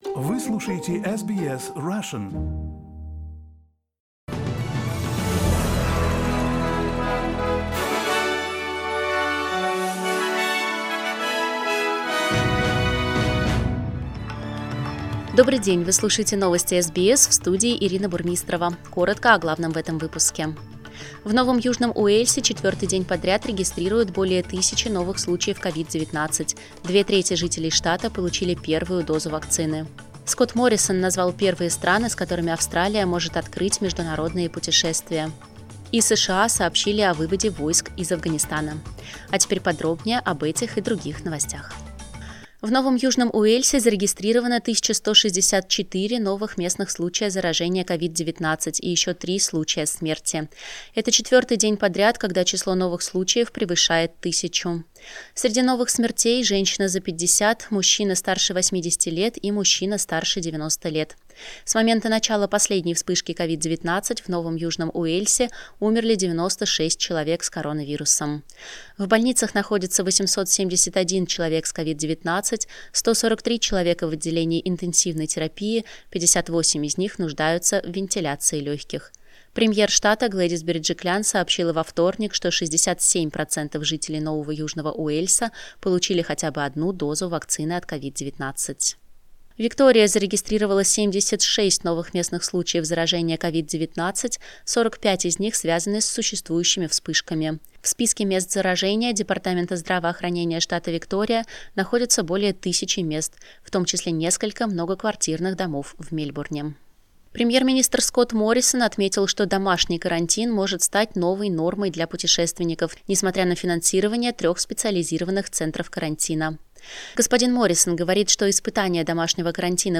Новости SBS на русском языке - 31.08